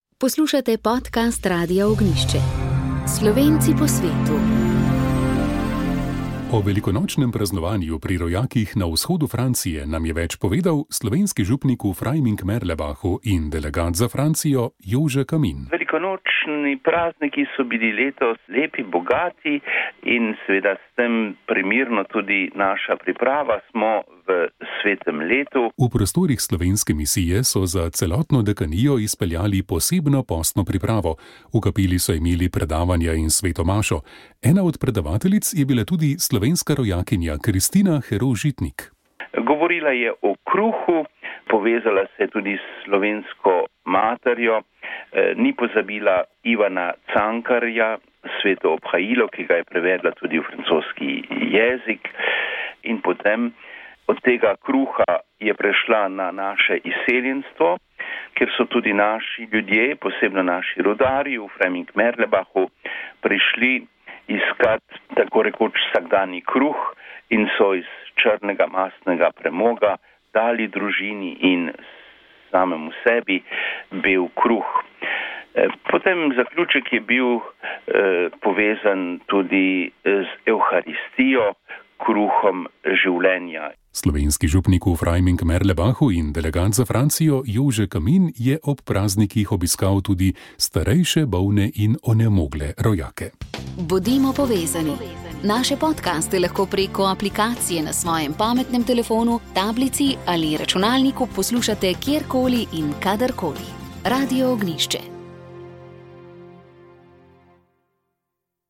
Pogovor z nekdanjim predsednikom državnega sveta Alojzem Kovšco na Goriških večerih
Ne bodite tiho, je nedavno na Goriškem večeru pod okriljem Združenja za vrednote slovenske osamosvojitve zbrane pozval nekdanji predsednik Državnega sveta Alojz Kovšca. Zdaj, ko ni več na tej funkciji, je lahko bolj brez dlake na jeziku, je večkrat ponovil v skoraj dve urnem pogovoru v Novi Gorici.